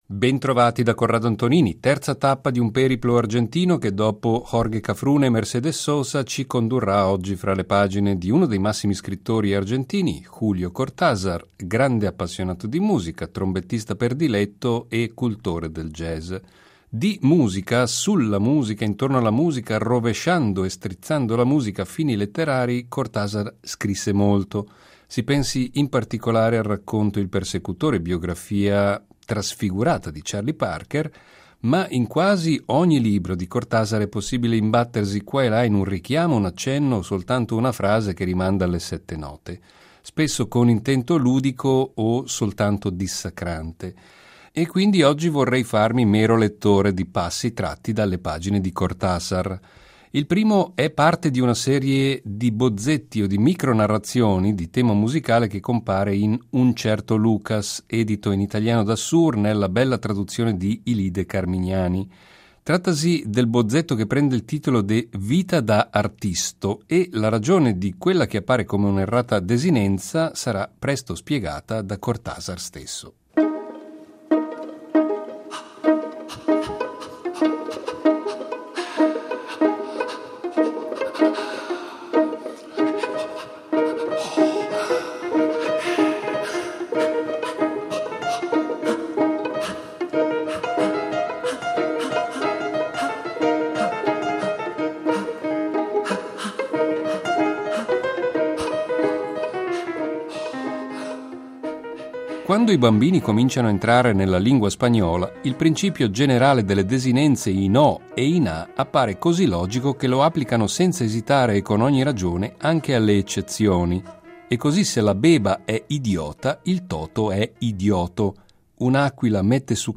“Clandestini per scelta” dedica un’intera puntata a Julio Cortázar , proponendo delle letture d’argomento musicale tratte dai suoi libri.